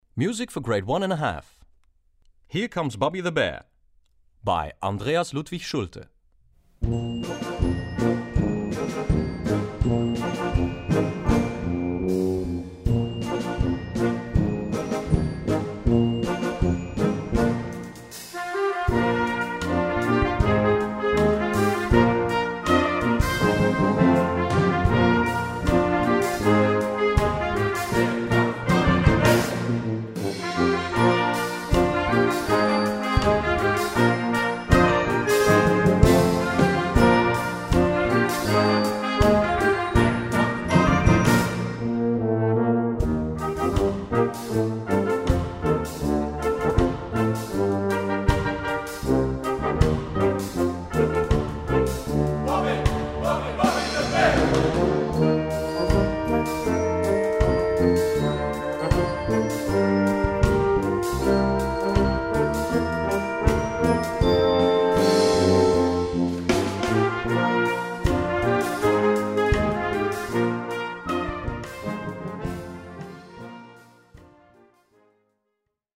A4 Besetzung: Blasorchester PDF